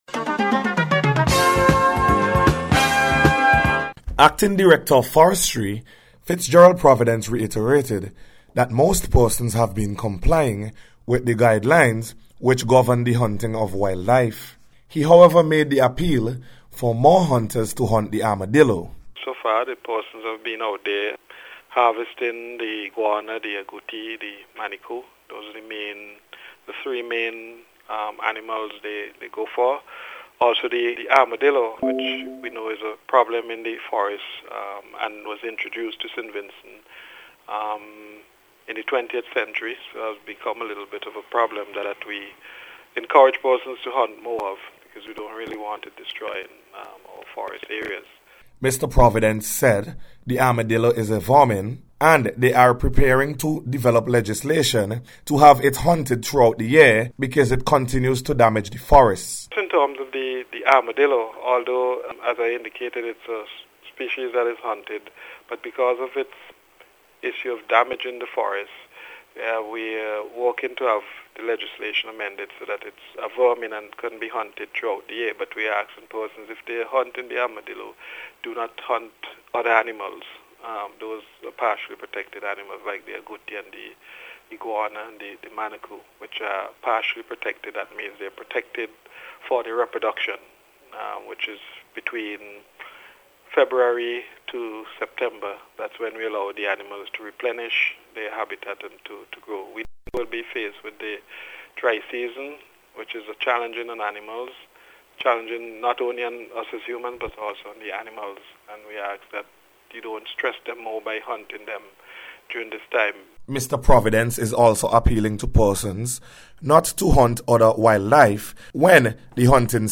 HUNT-ARMADILLO-REPORT.mp3